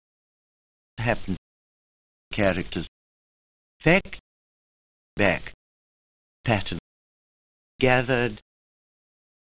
All the recordings have been subjected to lossy MP3 compression at some time during their lives.